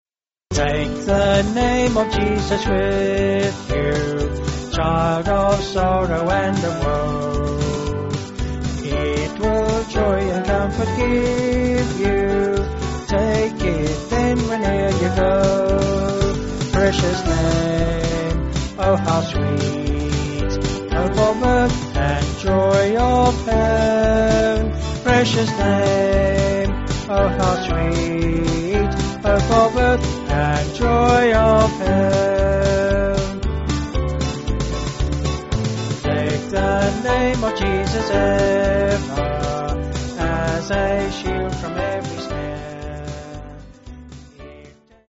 4/G-Ab
Vocals and Band